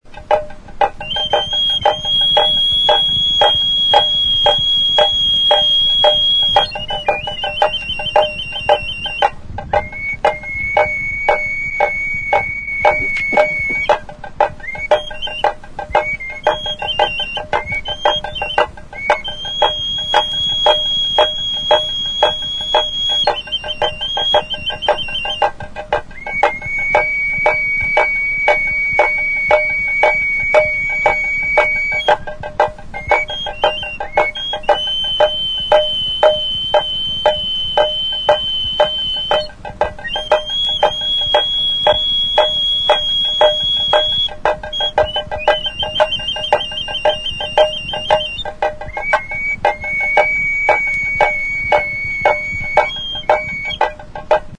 Instruments de musiqueTAMBOR
Membranophones -> Frappés -> Frappés à l'aide des baguettes
Flautarekin batera jole bakar batek jotzen du. Esku batekin flauta eta bestearekin danborra.
Larruzko danbor txikia da, makilarekin.